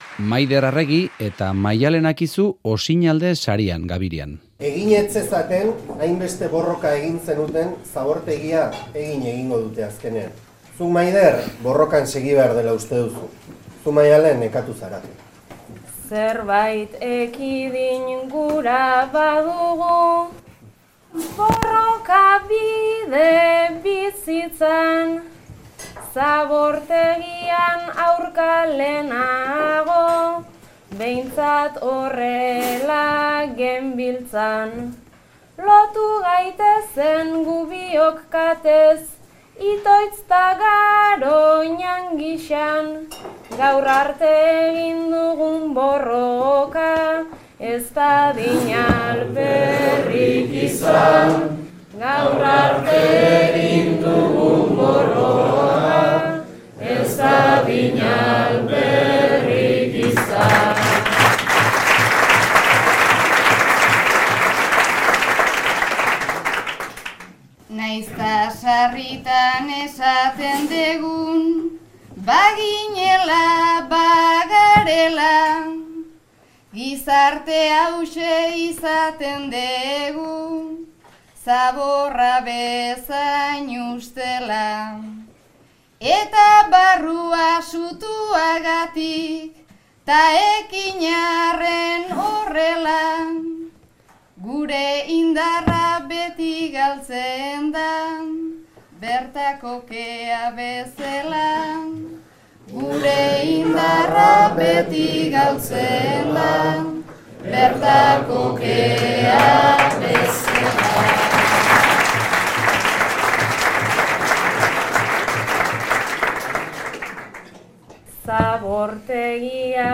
Osinaldeko finalean gabirian egindako bertsoaldia.